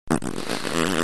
Now use your tongue and cheeks to force the air out between your lips…
Now do the same thing again but this time take a breath in through the nose as you expel the air out through your lips.